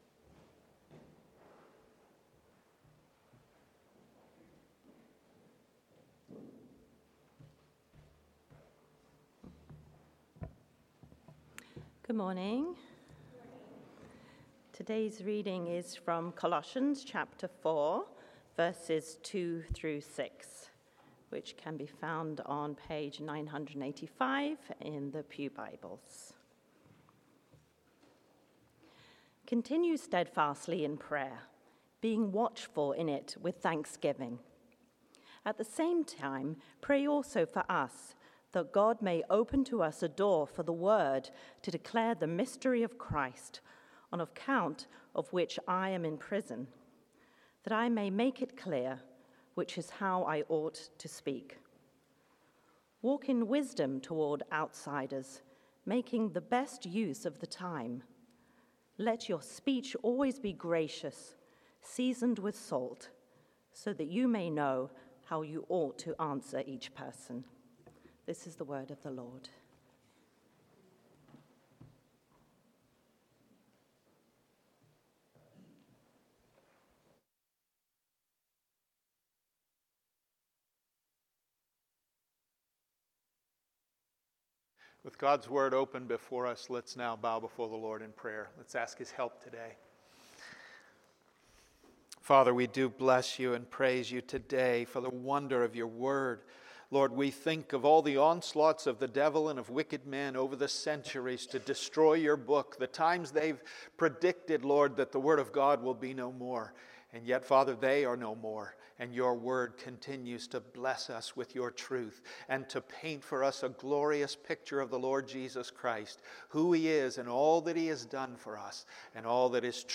Passage: Colossians 4:2-6 Sermon